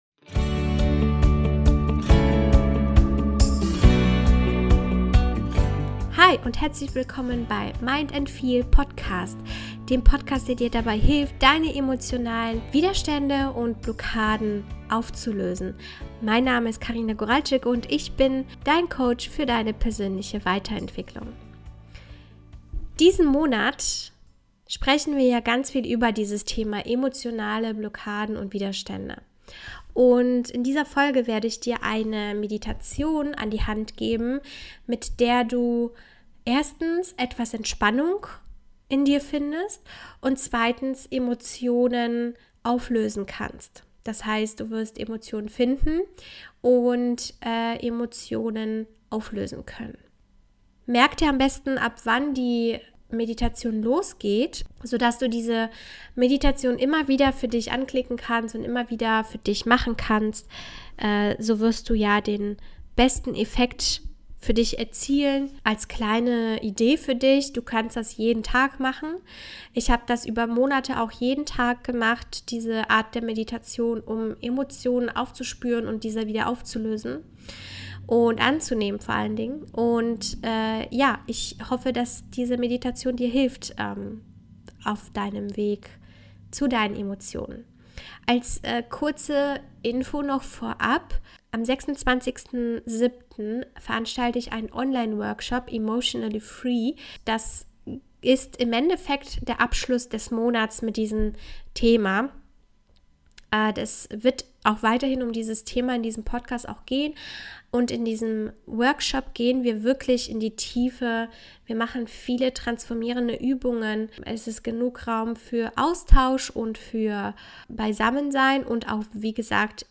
#006 - Geführte Meditation - Negative Emotionen loslassen ~ Mind & Feel Podcast